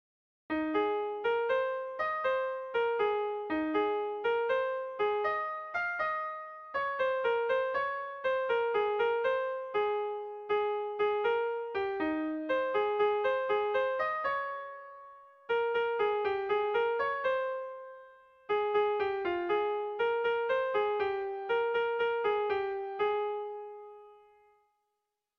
Air de bertsos - Voir fiche   Pour savoir plus sur cette section
Kontakizunezkoa
Zortzikoa, berdinaren moldekoa, 6 puntuz (hg) / Sei puntukoa, berdinaren moldekoa (ip)
ABD...